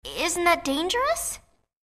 Voice 1 -
Sex: Female